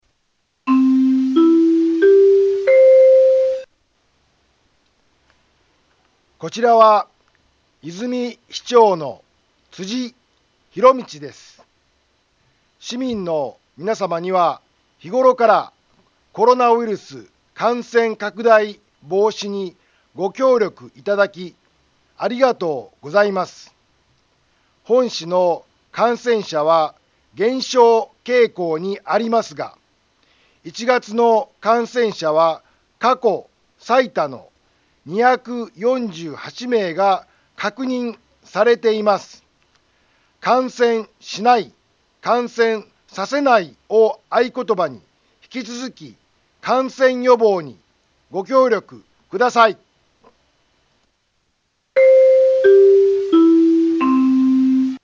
災害情報
Back Home 災害情報 音声放送 再生 災害情報 カテゴリ：通常放送 住所：大阪府和泉市府中町２丁目７−５ インフォメーション：こちらは、和泉市長の辻 ひろみちです。